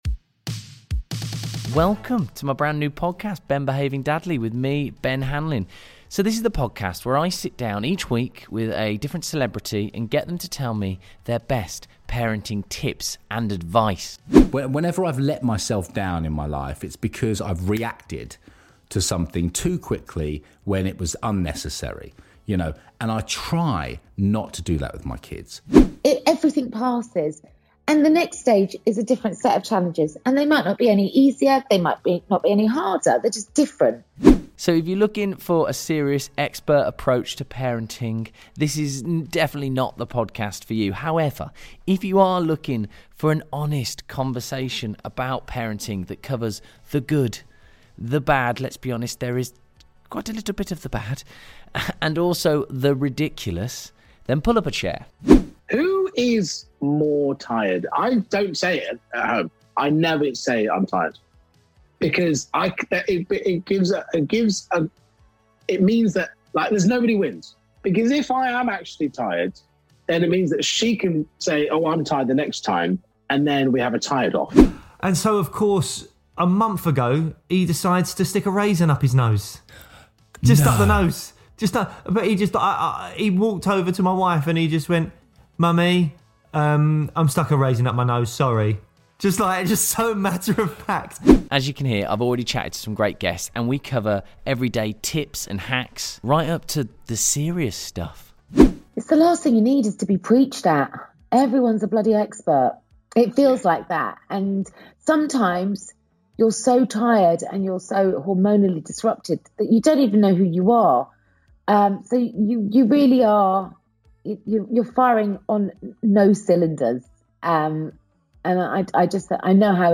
In this parenting pod, Ben Hanlin sits down with celebrity guests to get their best parenting advice. From the small hacks, right up to the big stuff!